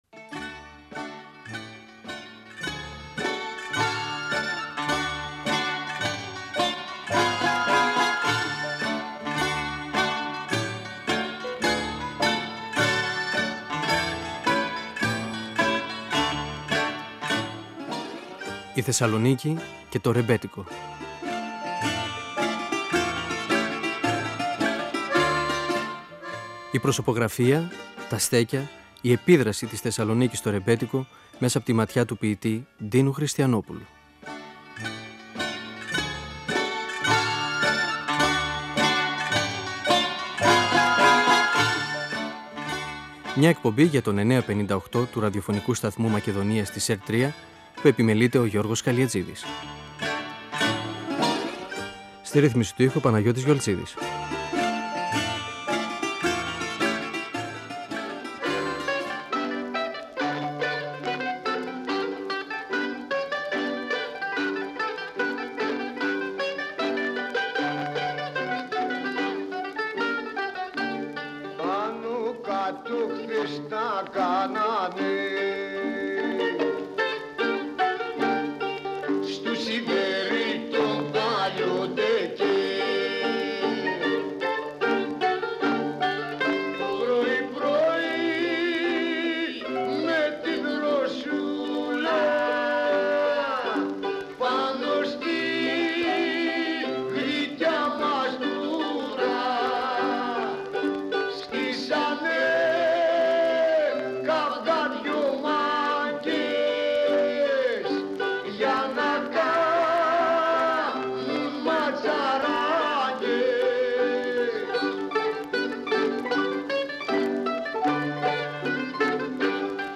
Ο ποιητής Ντίνος Χριστιανόπουλος (1931-2020) μιλά για όσους μελέτησαν καιέγραψαν για τα στέκια του ρεμπέτικου στη Θεσσαλονίκη. Αναφέρεται στις ταβέρνεςκαι τα καφενεία της περιοχής του Λευκού Πύργου και των οδών Π. Μελά καιΕθνικής Αμύνης.